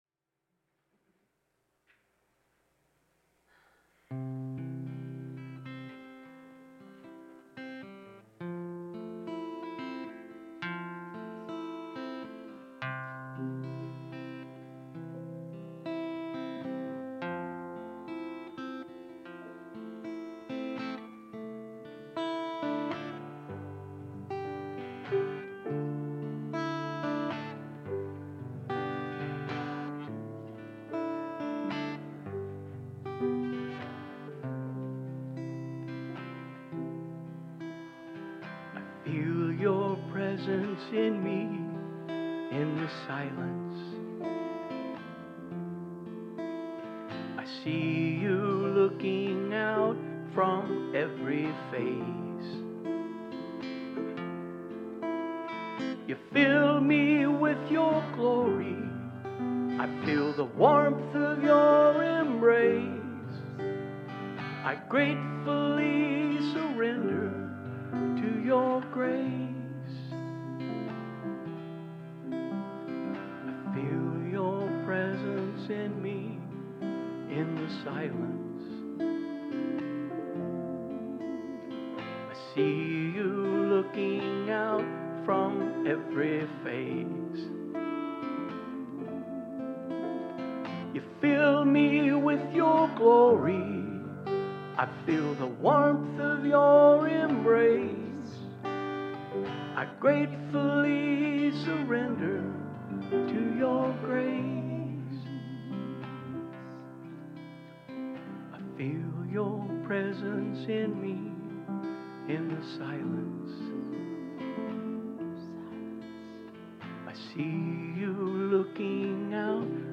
The audio recording (below the video clip) only includes the Meditation, Message, and Featured Song.